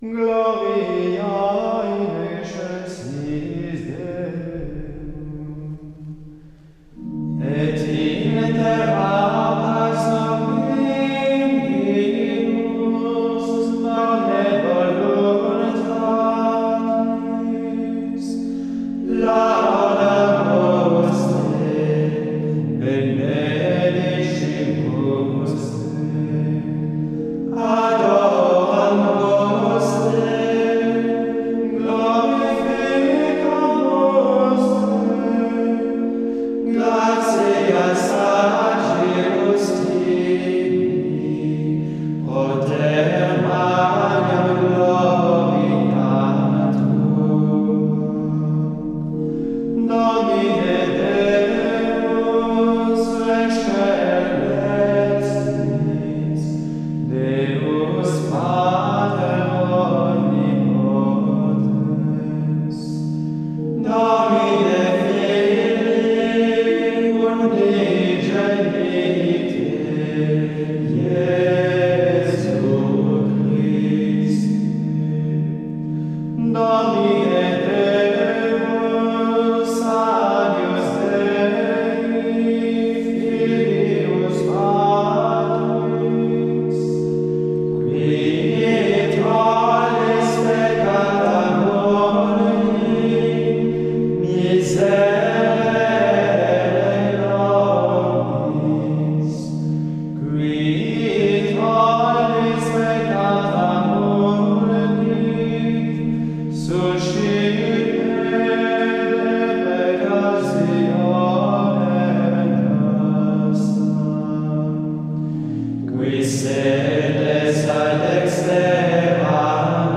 Il s’agit d’un 1er mode, bien unifié au plan modal, qui utilise des formules mélodiques largement reprises tout au long de la pièce.
L’intonation est assez originale, puisqu’elle part du La sur l’accent au levé de Glória, et descend doucement jusqu’au Ré, tonique du 1er mode. Cette intonation a donc quelque chose de recueilli, ce qui n’empêche pas qu’elle doit être légère et entraînante.
La vie de ce Glória tient à l’alternance entre les formules graves, en détente et en recueillement, et les passages aigus, tout en élan et en lumière.